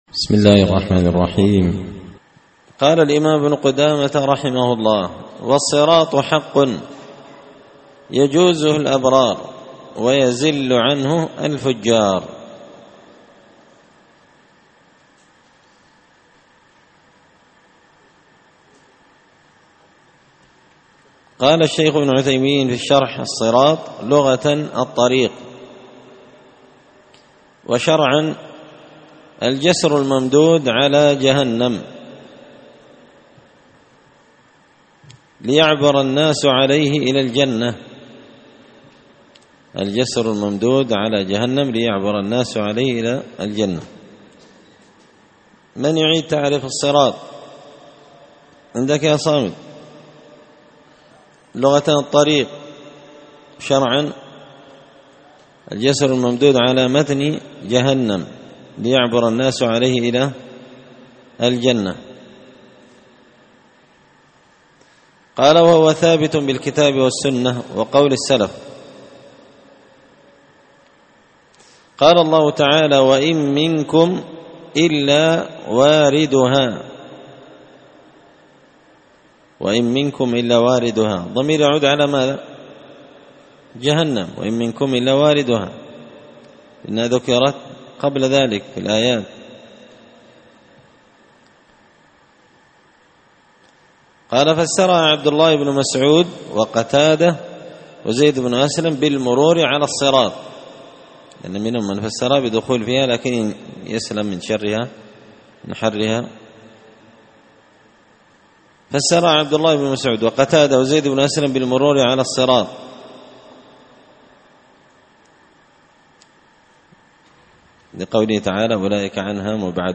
شرح لمعة الاعتقاد ـ الدرس 40
دار الحديث بمسجد الفرقان ـ قشن ـ المهرة ـ اليمن